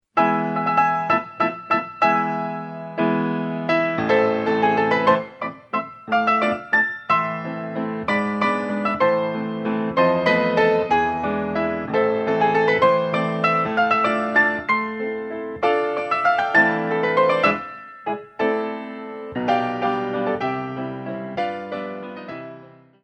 concert pianist
for intermediate ballet classes.